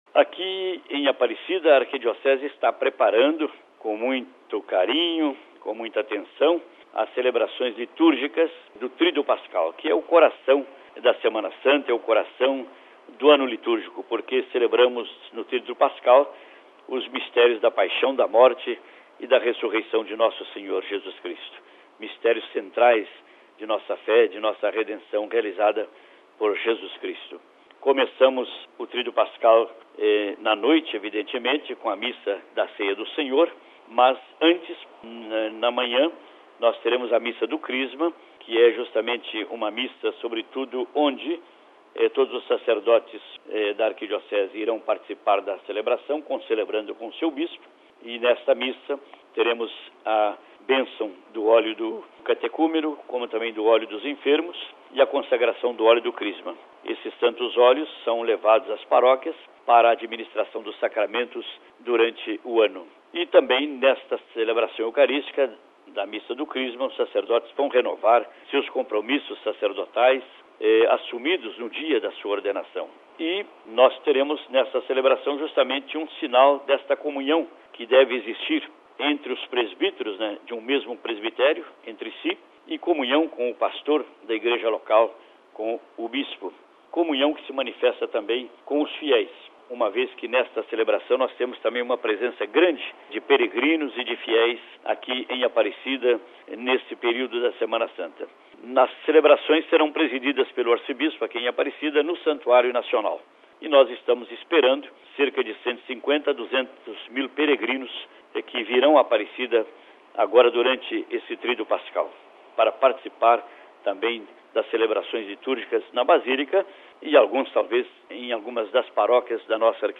Ouçamos o Presidente do Conselho Episcopal Latino-americano (CELAM), Dom Raimundo Damasceno Assis, Arcebispo de Aparecida, que nos fala sobre as atividades em sua arquidiocese nesta Semana Santa.